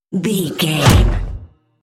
Trailer dramatic hit
Sound Effects
Atonal
heavy
intense
dark
aggressive
hits